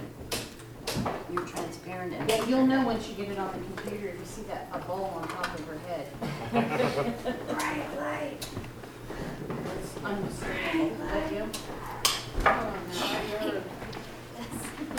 Mahanoy City, PA : Old Elks Theater
Electronic Voice Phenomenon (EVP)
Clip 3 (0038 hrs.)An investigator makes the comment during an EVP session in the concession stand “come on you’re being too quiet” a faint noise was heard at the time and recording.